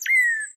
animalia_cardinal.1.ogg